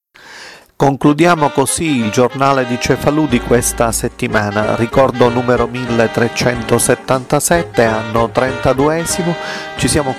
for clarity when I extract tracks from audio files (mp3) and then mix them together with copy-paste to obtain one only audio mixes, overlaps.
assembly is almost exclusively of files not talked music. the file is recorded spoken unnecessarily in stereo that’s why it would be useful to have audacity automatically from one tracccia like a mono file and also because in this way the screen is part of a larger number of files.